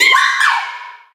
Audio / SE / Cries / MIMIKYU.ogg
MIMIKYU.ogg